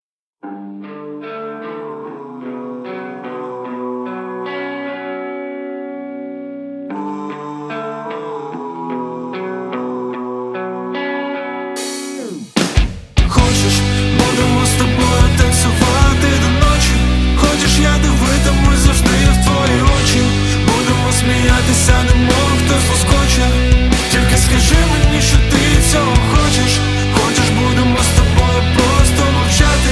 Жанр: Поп / Инди / Украинские